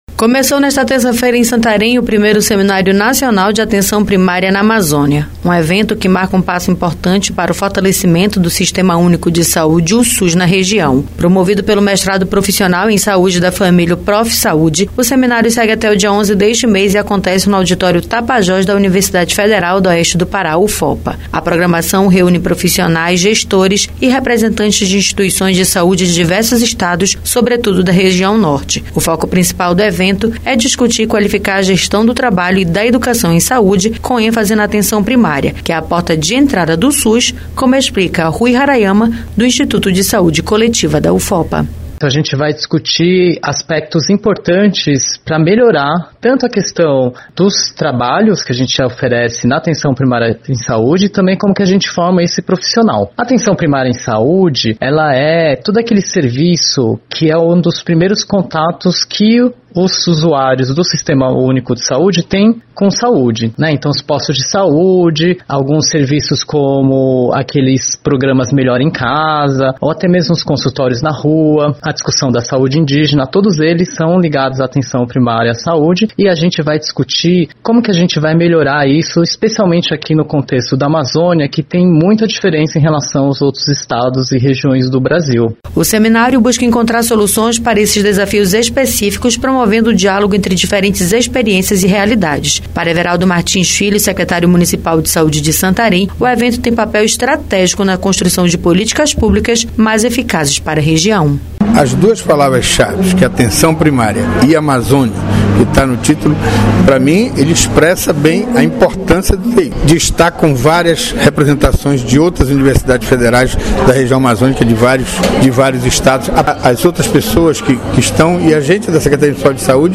O objetivo do evento, que reúne profissionais, gestores e representantes de instituições de saúde de diversos estados, sobretudo da região Norte, é discutir e qualificar a gestão do trabalho e da educação em saúde, com ênfase na Atenção Primária, que é a porta de entrada do SUS. A reportagem